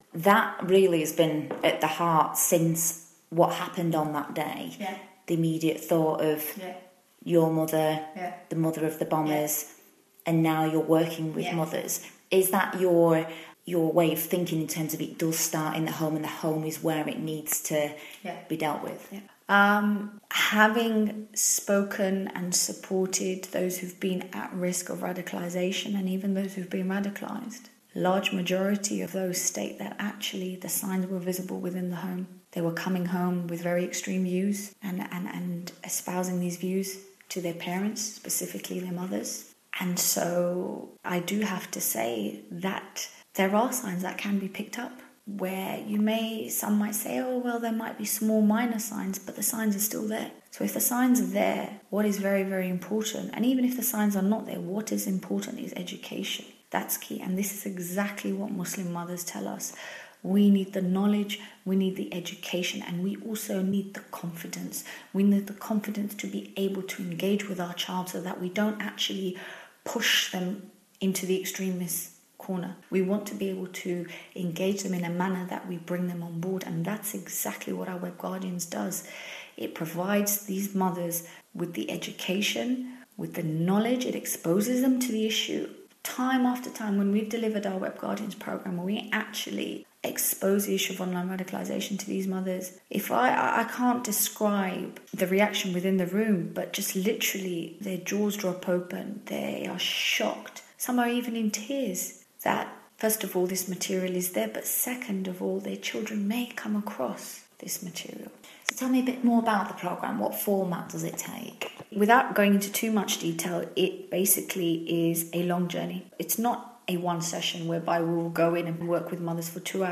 Questions and Answers